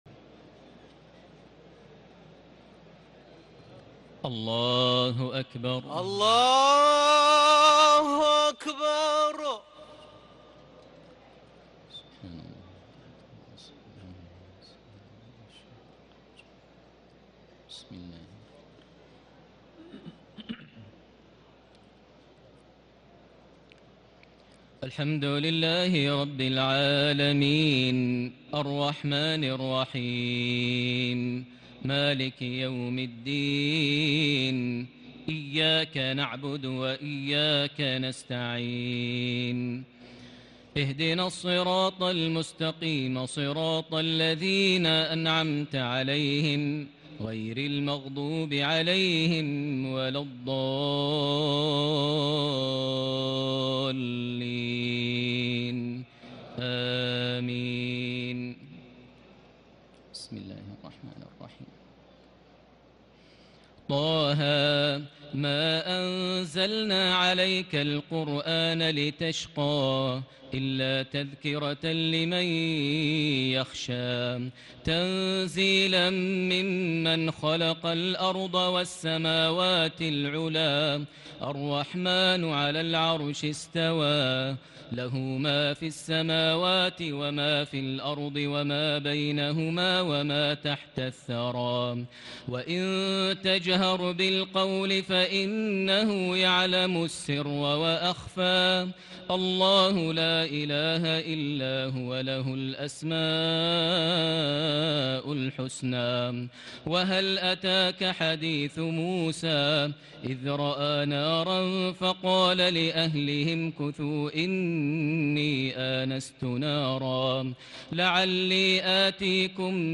تراويح الليلة الخامسة عشر رمضان 1440هـ سورة طه كاملة Taraweeh 15 st night Ramadan 1440H from Surah Taa-Haa > تراويح الحرم المكي عام 1440 🕋 > التراويح - تلاوات الحرمين